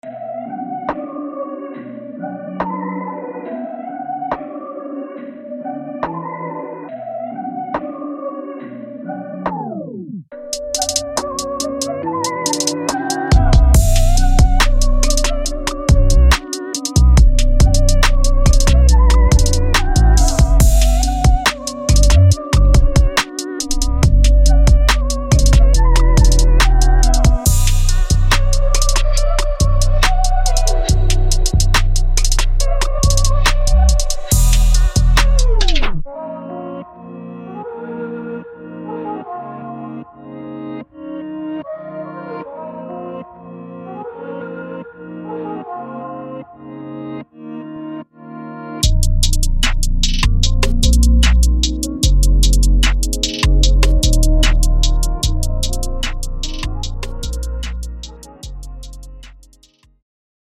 这是一个安静的、下雨的、探索霓虹灯下的大都市的理想背景音乐。